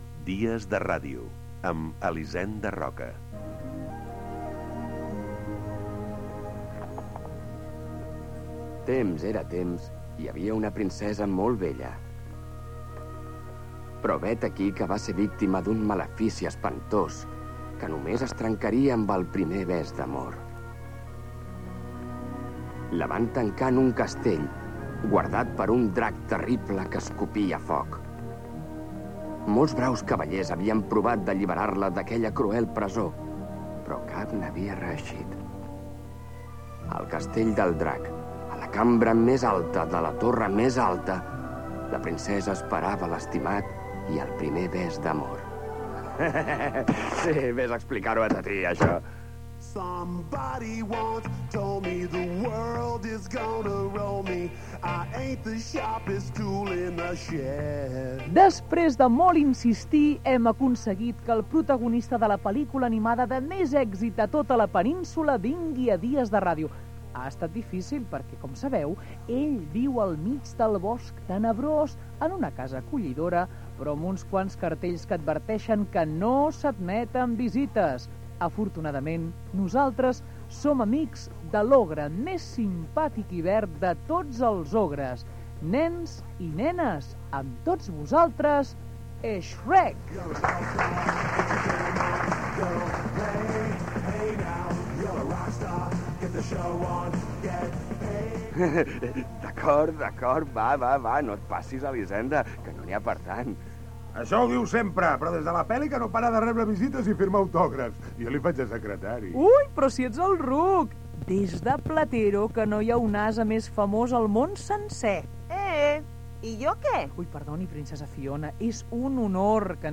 Identificació del programa, lectura d'un fragment de "Shrek", espai dedicat al seu protagonista, l'asa i la princesa Fiona.
Entreteniment